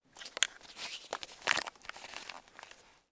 CAMERA_DSLR_Handle_01_mono.wav